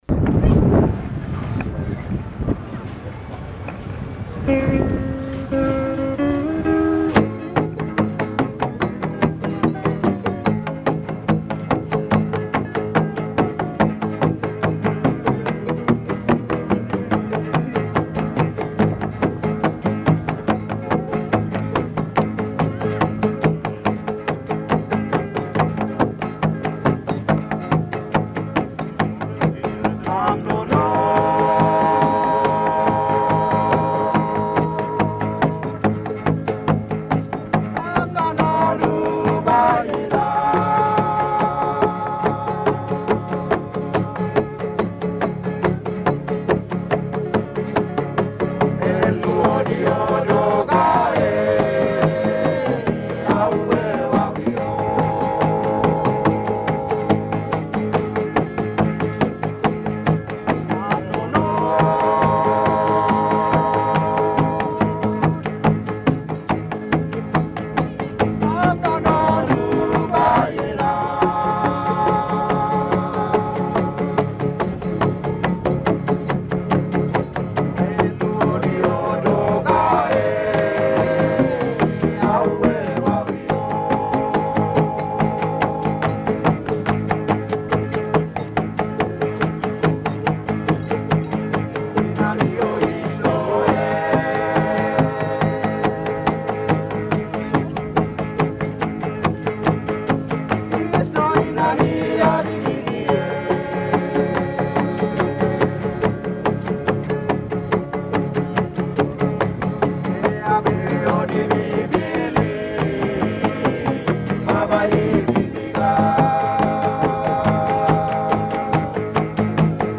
I captured some of their music on my walkman-like device, including my talking to them in my attempt at Tok Pisin (the pidgin English spoken in New Guinea.)
men playing music on bamboo pipes with thongs (flip-flops) at Goroka Highlands show, Papua New Guinea, 1984 men playing music on bamboo pipes with thongs (flip-flops) at Goroka Highlands show, Papua New Guinea, 1984